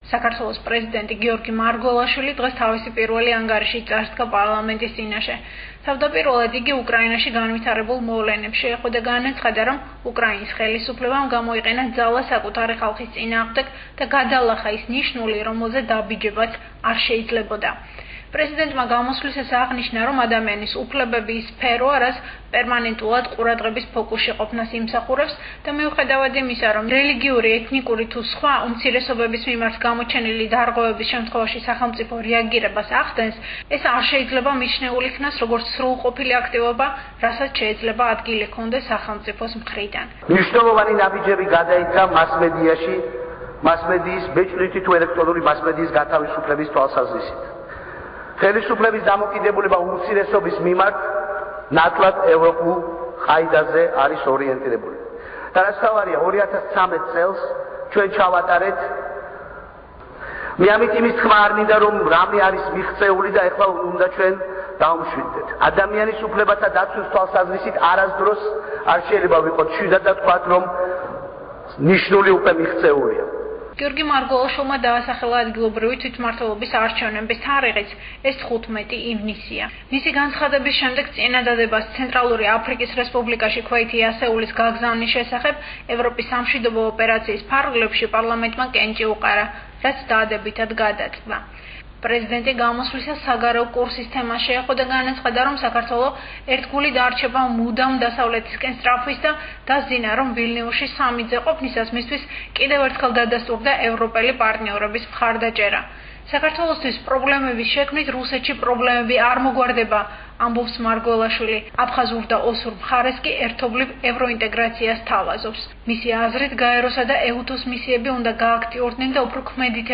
პრეზიდენტის პირველი გამოსვლა პარლამენტში
პრეზიდენტის ყოველწლიური გამოსვლა